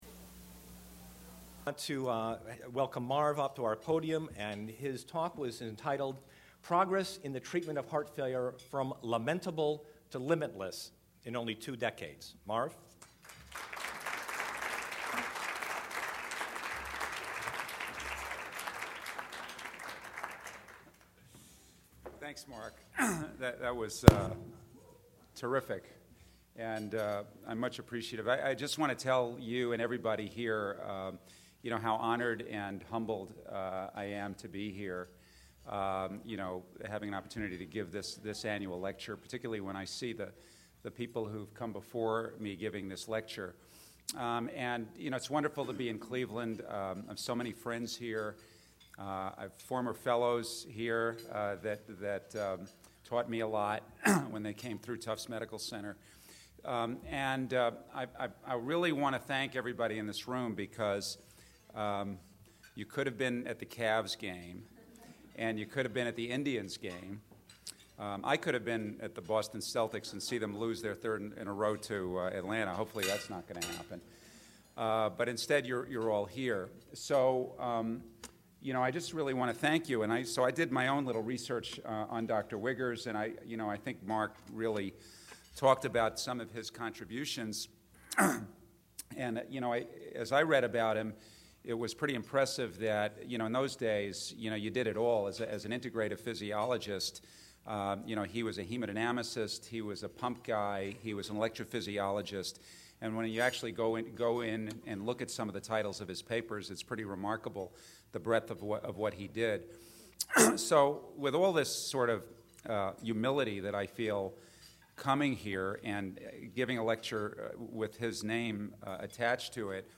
lecture